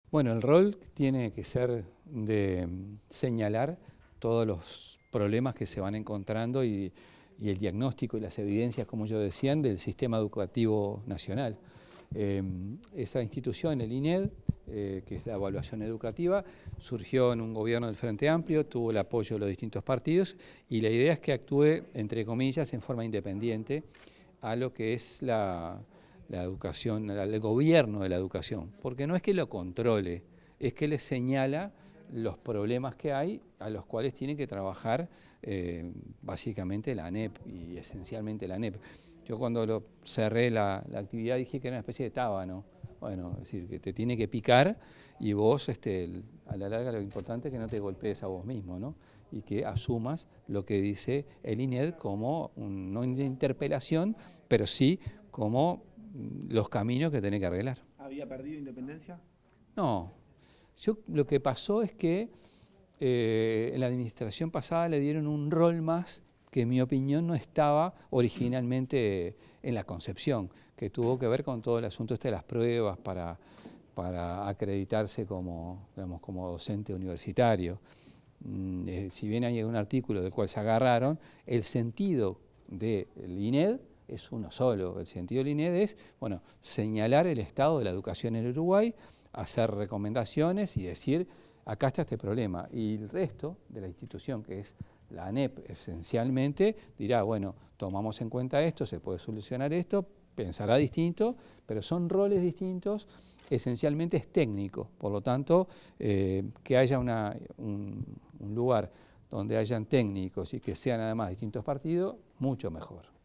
Declaraciones del ministro de Educación y Cultura, José Carlos Mahía
El ministro de Educación y Cultura, José Carlos Mahía, dialogó con la prensa luego de la presentación de las autoridades del Instituto Nacional de